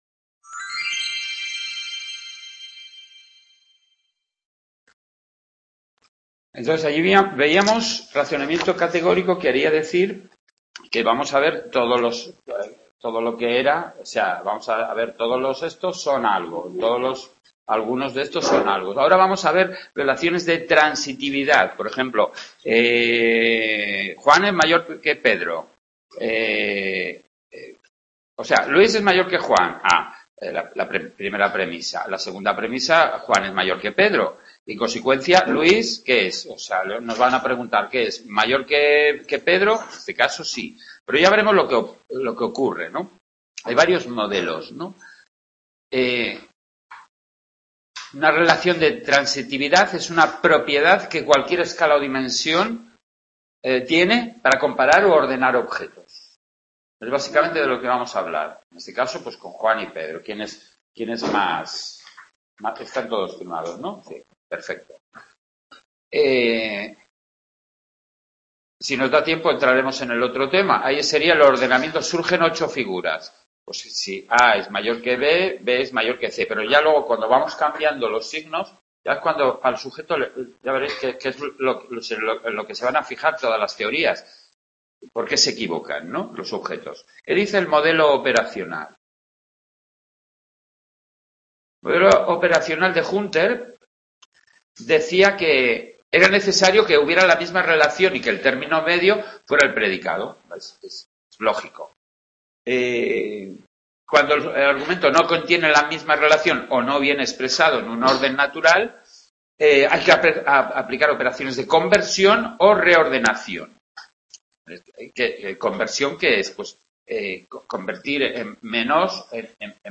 Grabación INTECCA de la segunda parte del tema 4: Razonamiento silogístico: Categórico y Transitivo, de Psicología del Pensamiento. Realizada en el Aula de Sant Boi